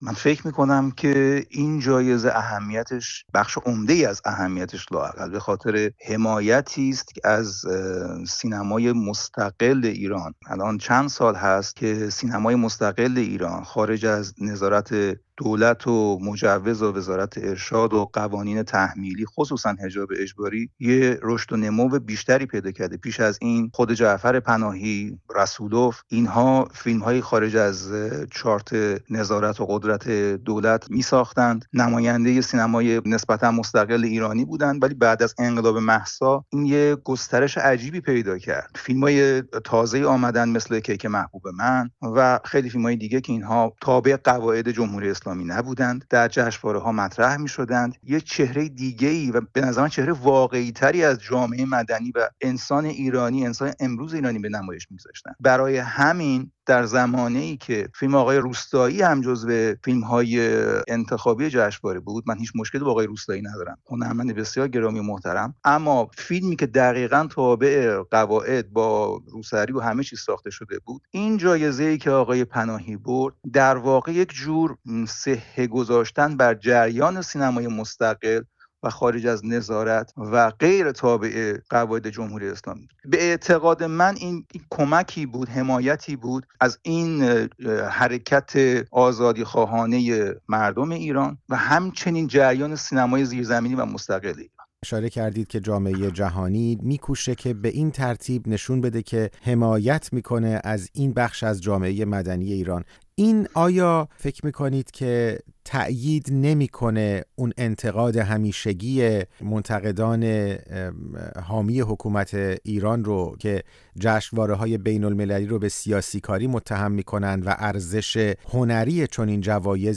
نخل طلای جعفر پناهی و آزادی‌خواهی مردم ایران در گفت‌وگو با مانا نیستانی